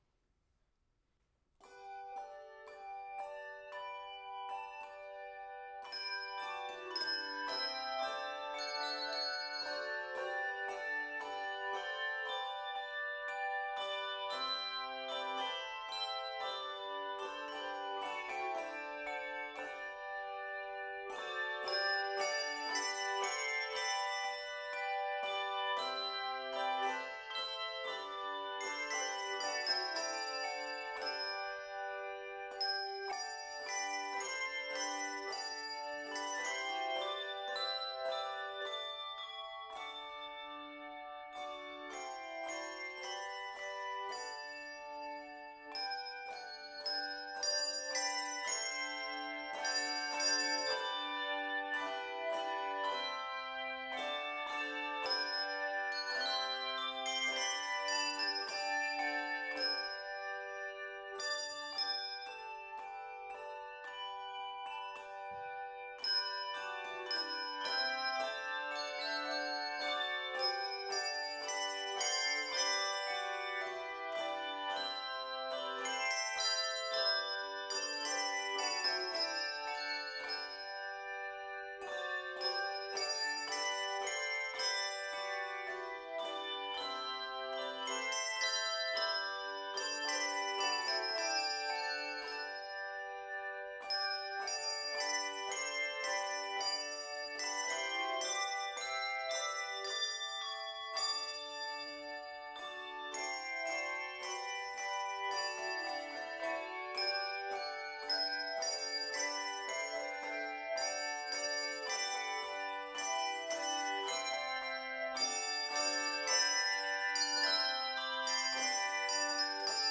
Voicing: Handbells 2-3 Octave